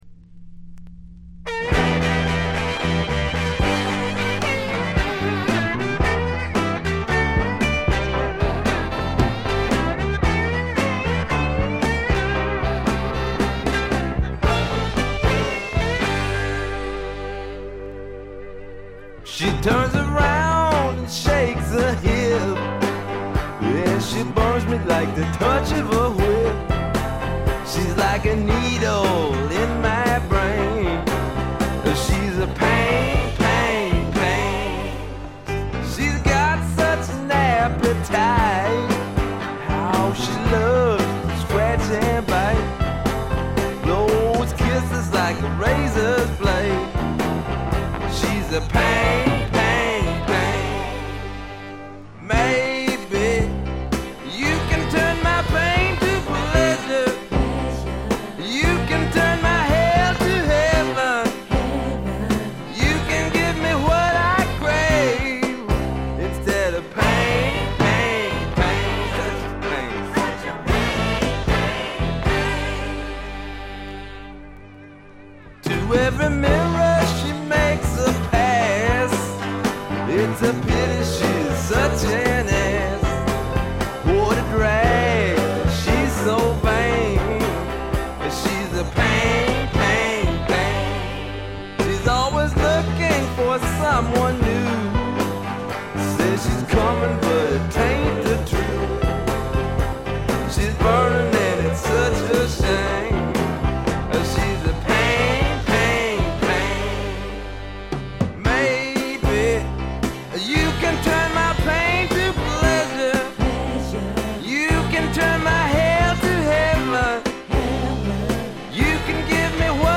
よりファンキーに、よりダーティーにきめていて文句無し！
試聴曲は現品からの取り込み音源です。